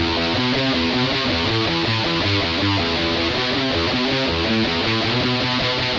Am To Em Back To Am 电吉他 110 Bpm
描述：电吉他上的几个和弦可以营造一种气氛。
Tag: 110 bpm Cinematic Loops Guitar Electric Loops 751.81 KB wav Key : Unknown